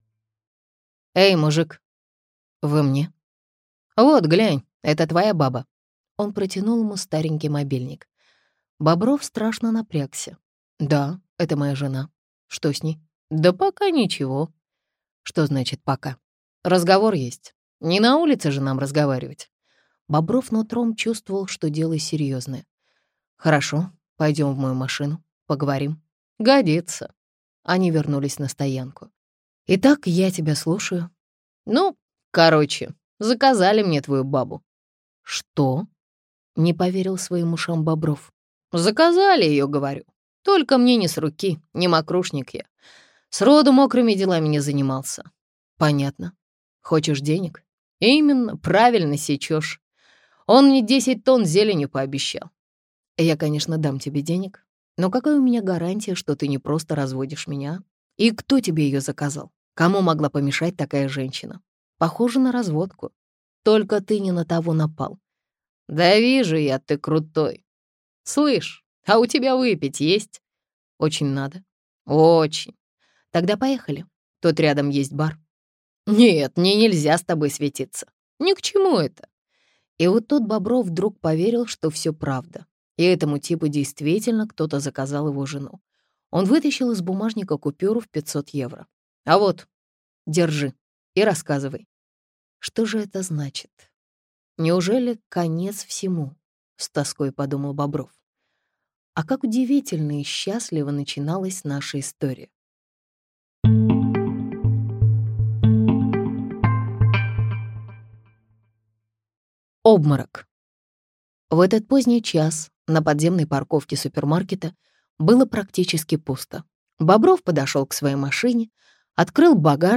Аудиокнига Вафли по-шпионски - купить, скачать и слушать онлайн | КнигоПоиск